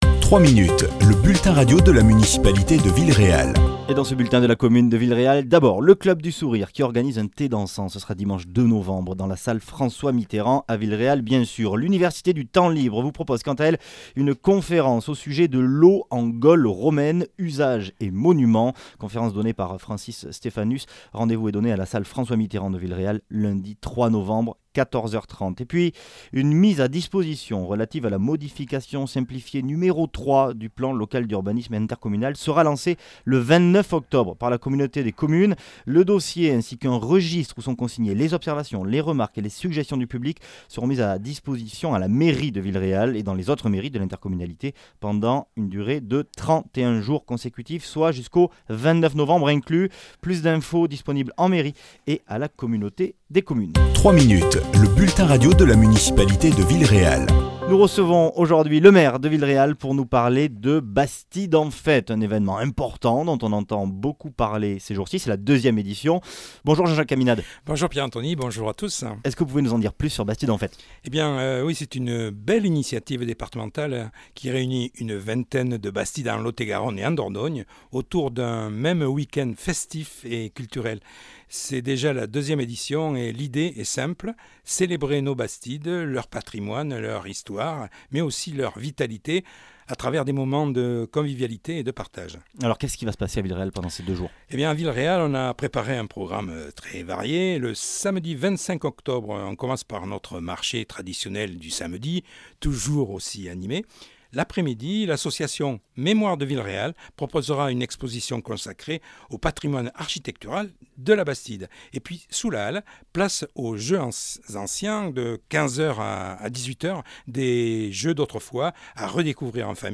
Coup de projecteur sur la 2ème édition de "Bastides en fête", organisée par le comité départemental du tourisme et qui met à l'honneur de nombreuses bastides de Lot-et-Garonne et Dordogne, dont Villeréal. Les explications du maire Jean-Jacques Caminade.